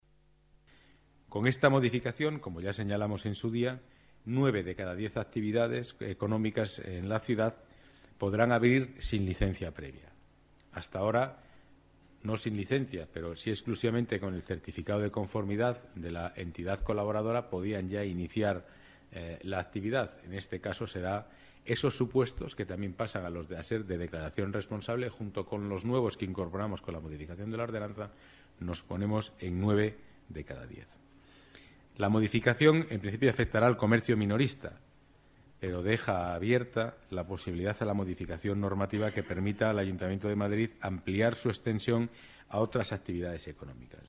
Nueva ventana:Declaraciones del delegado de Economía, Empleo y Participación Ciudadada, Pedro Calvo